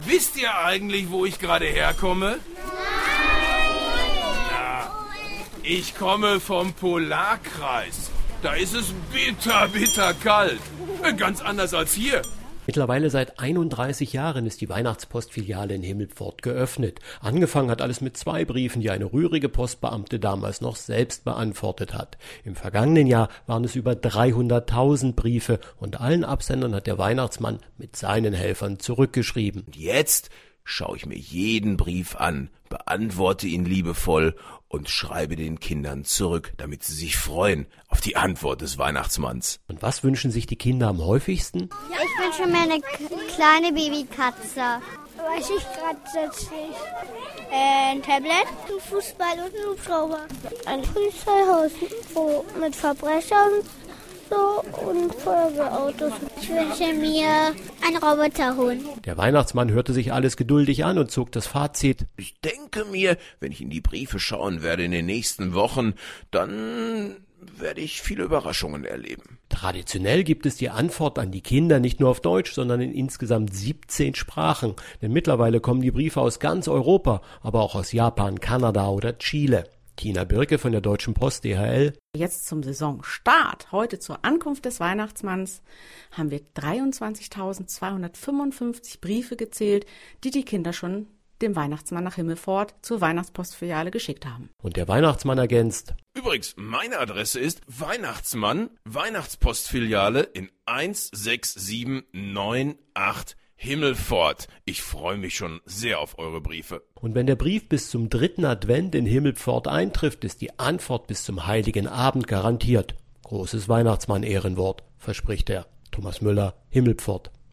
O-Töne / Radiobeiträge, ,